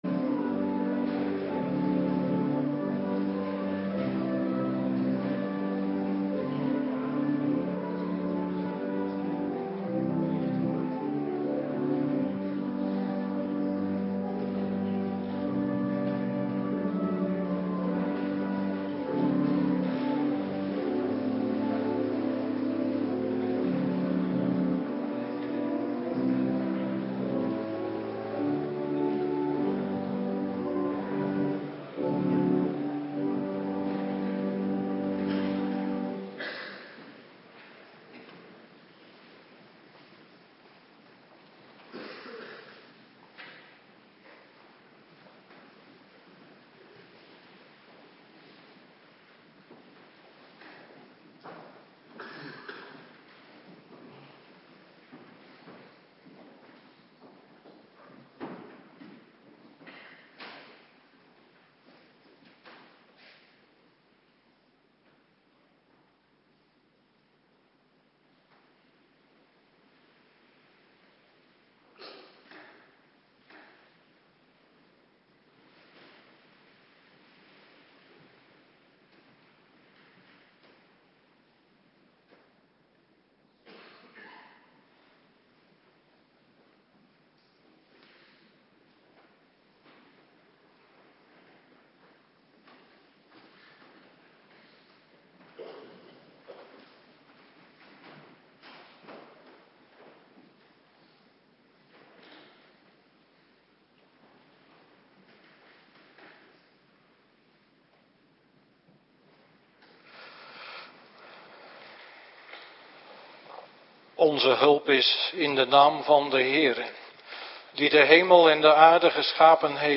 Avonddienst